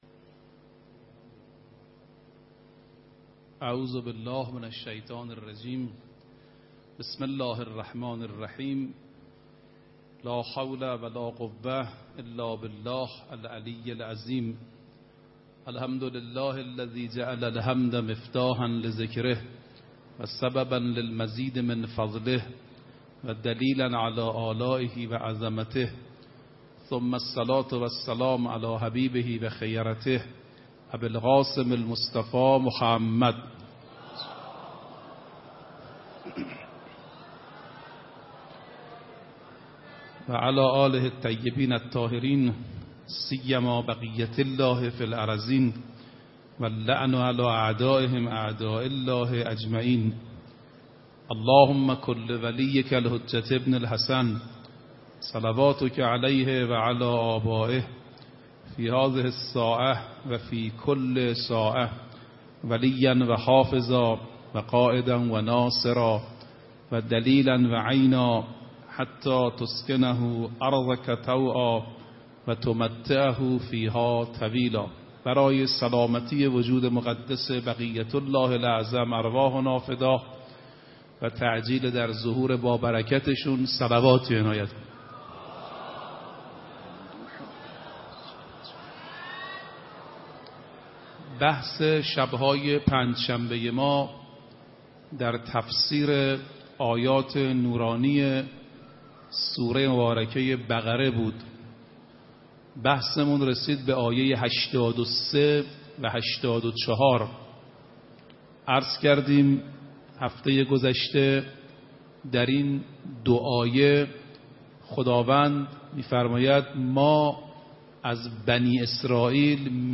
4 بهمن 96 - حرم حضرت معصومه - پیمان های بنی اسرائیل
هفتگی سخنرانی